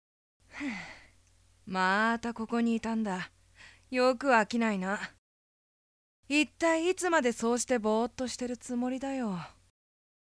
【サンプルセリフ】